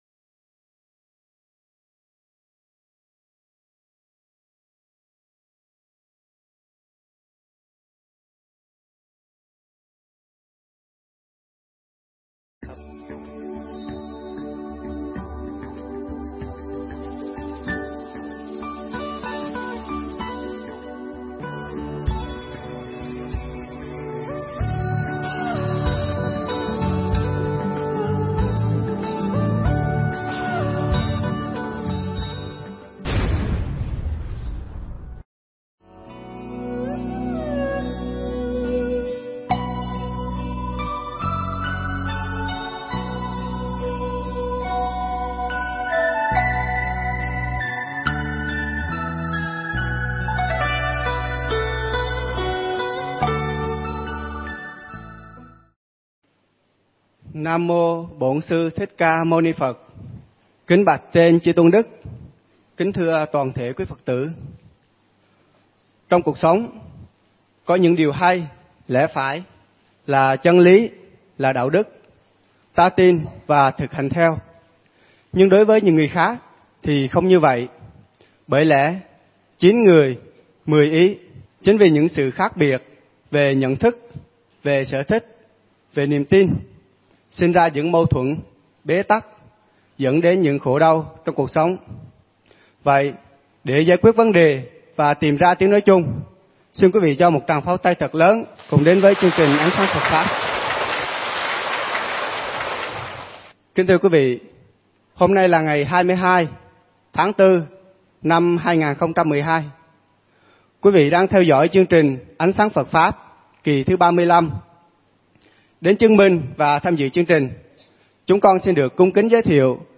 Nghe Mp3 thuyết pháp Ánh Sáng Phật Pháp Kỳ 35